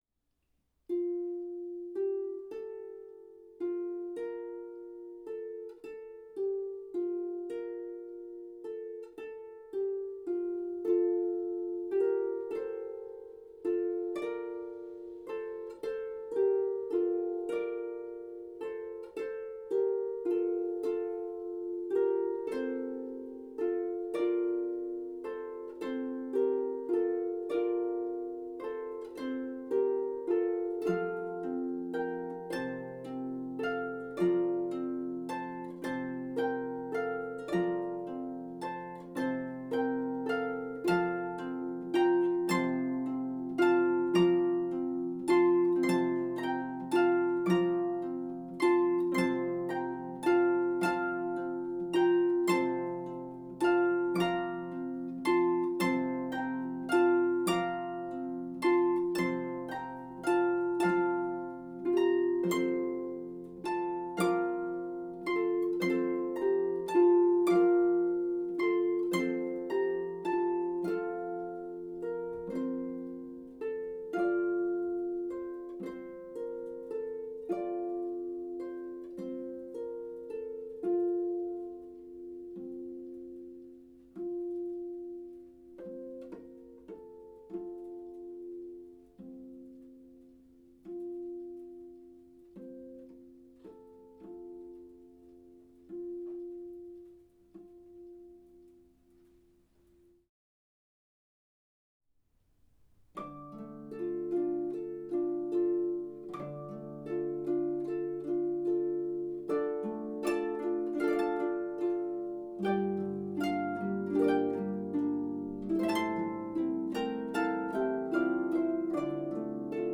three brief lullaby arrangements
lever or pedal harp trio